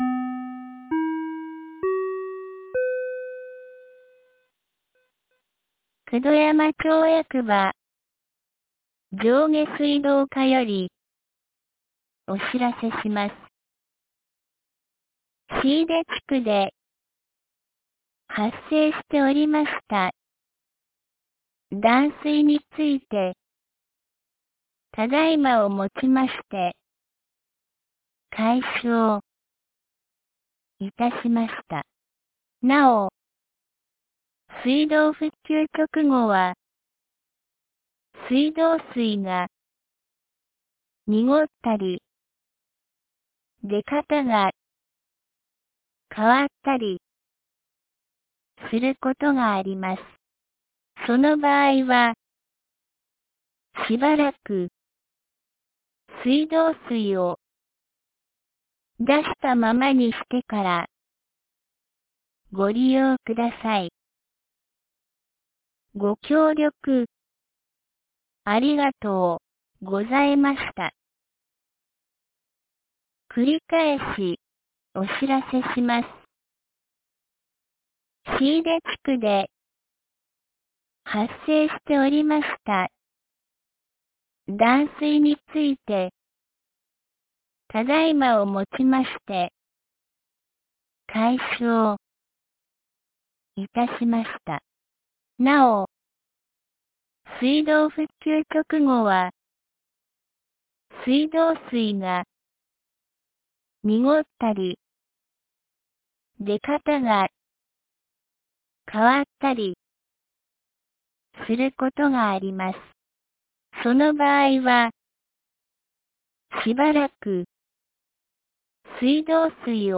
2023年06月03日 16時42分に、九度山町より椎出地区へ放送がありました。
放送音声